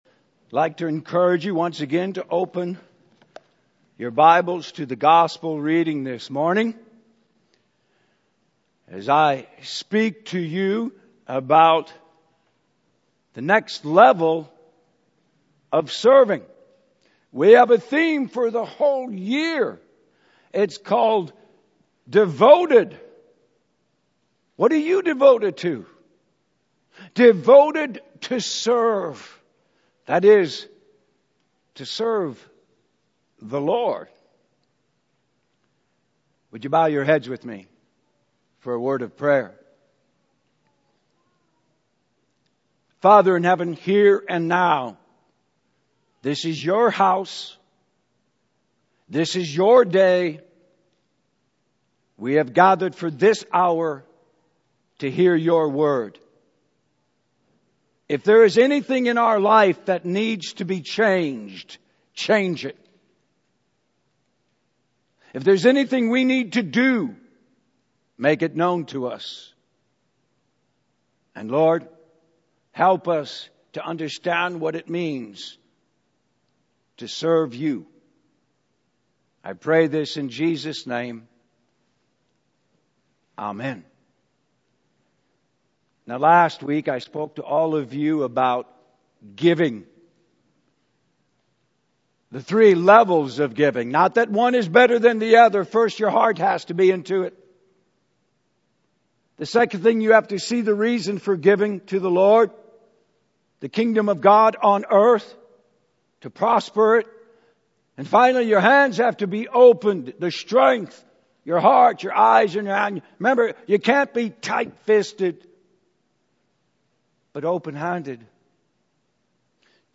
Message Listen Service In our self-serving culture with it's 'me-first' mentality, acting like a servant is not a popular concept.